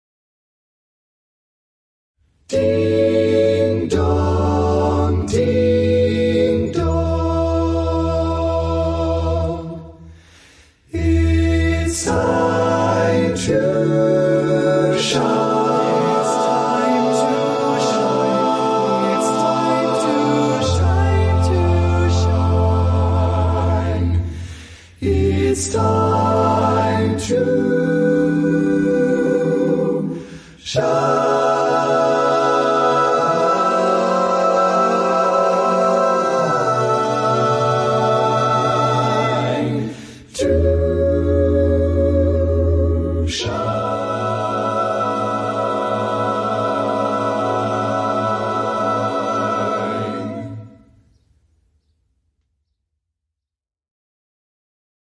Key written in: A Major
How many parts: 6
Type: Other male
All Parts mix:
Learning tracks sung by